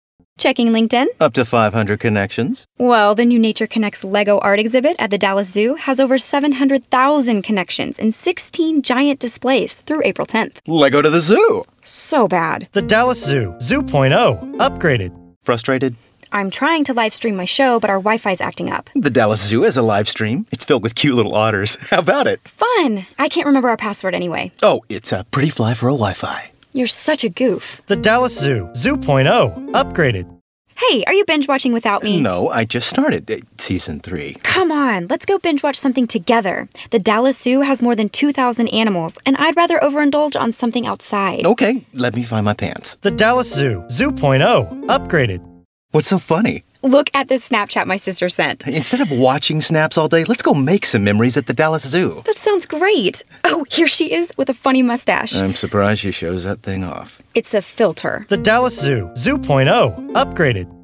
Dallas Zoo Spot
Dallas-Zoo-Radio-Spots-copy.mp3